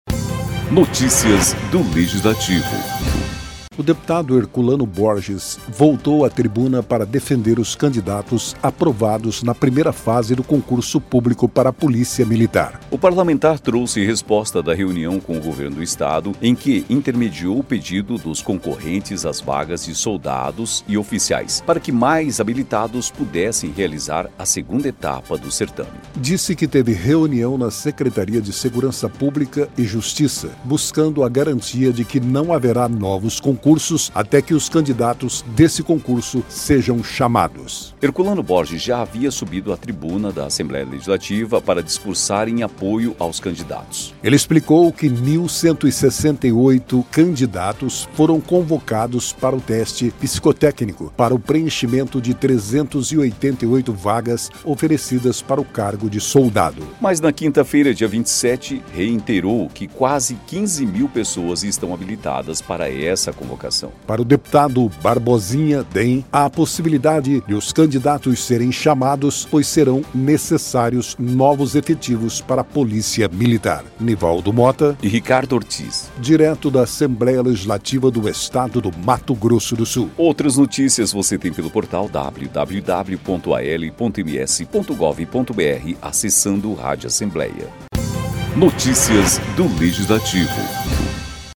O deputado Herculano Borges (SD) voltou à tribuna para defender os candidatos aprovados na primeira fase do Concurso Público para a Polícia Militar.